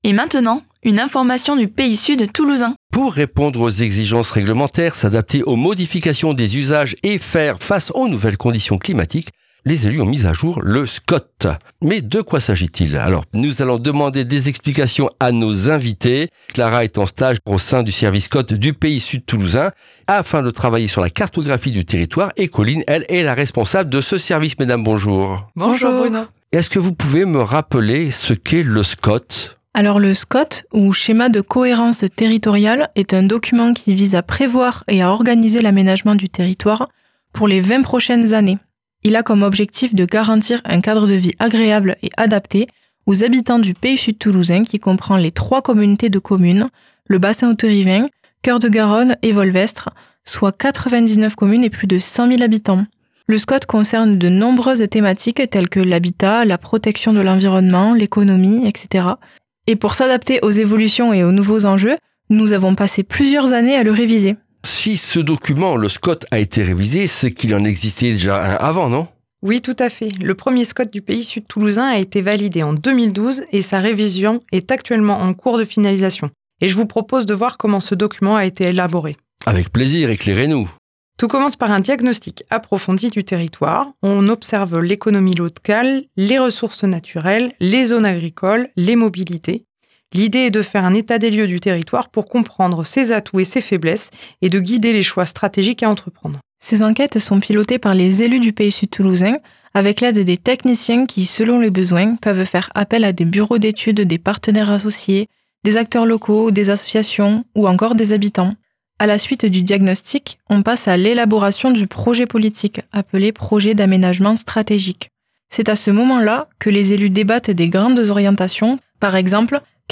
Retrouvez ici les brèves capsules radiophoniques de 5 min qui  servent d’introduction